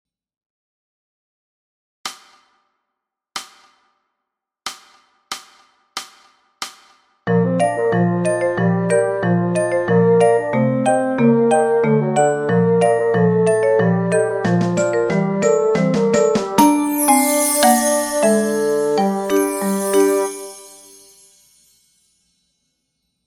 Dans ce potiron – chiffre 2 à 92 bpm